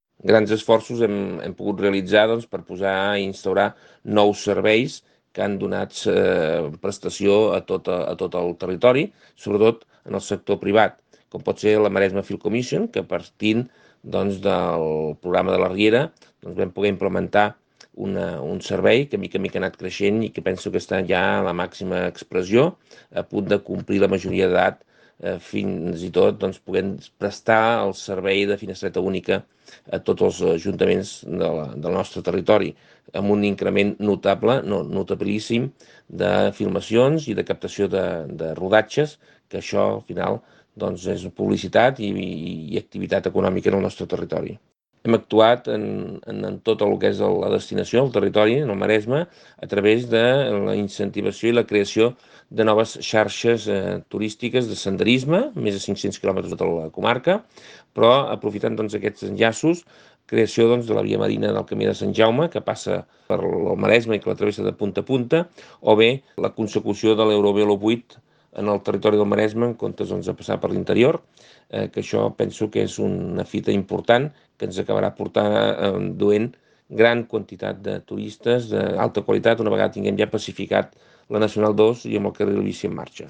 Joaquim Arnó, President Consorci (2015-2023)
Nota de veu Joaquim Arnó - Consorci